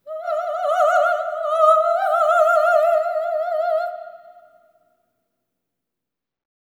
OPERATIC14-R.wav